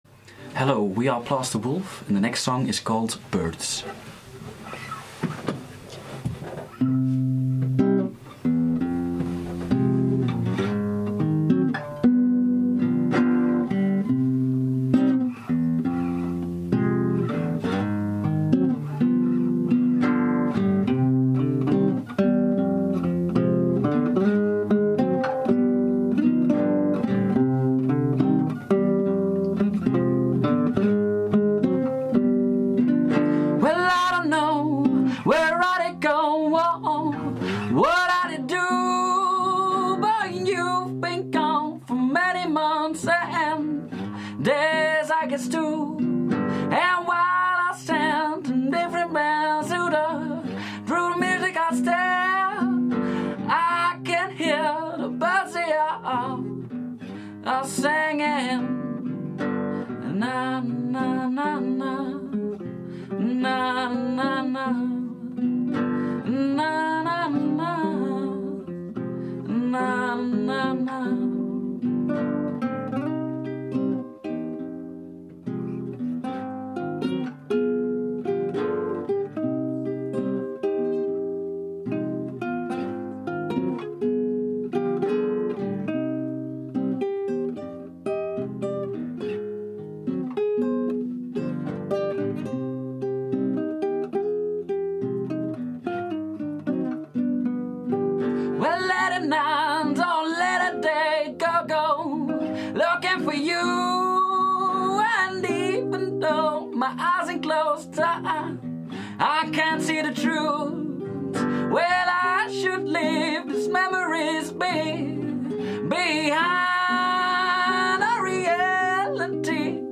Minimal Soul
Hier der gesamte Liveauftritt zum Nachhören.